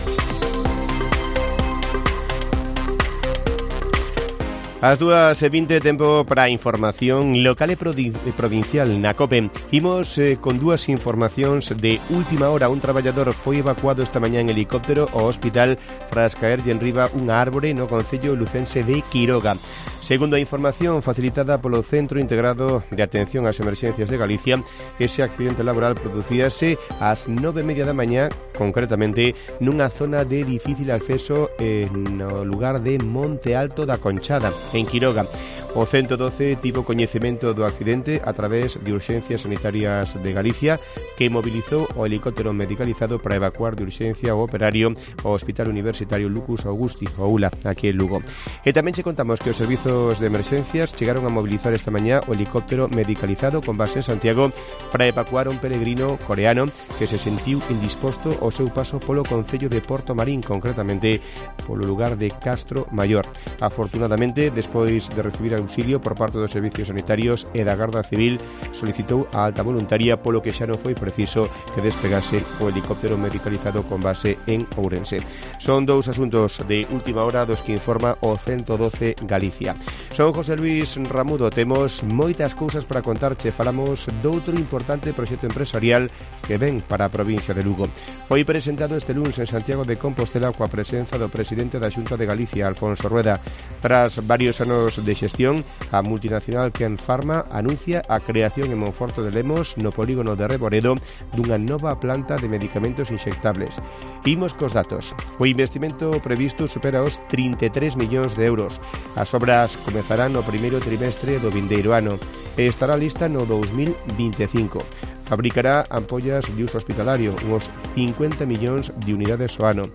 Informativo Mediodía de Cope Lugo. 12 de septiembre. 14:20 horas